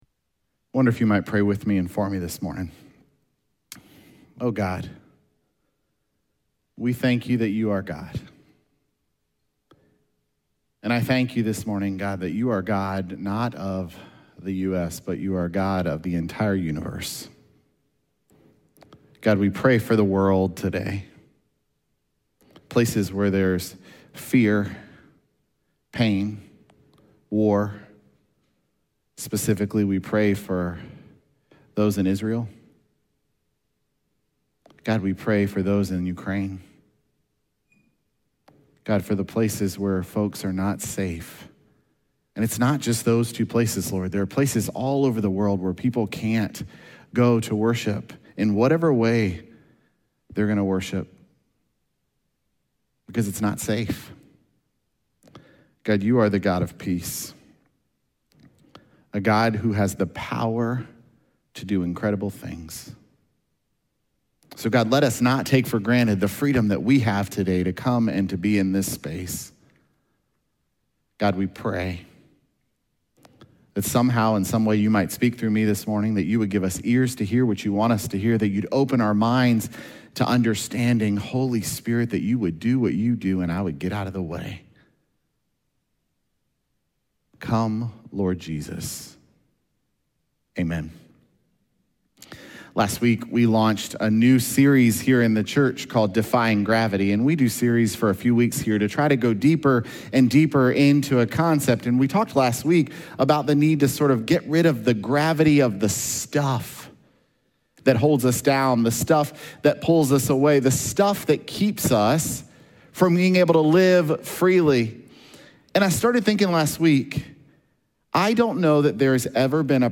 Oct8Sermon.mp3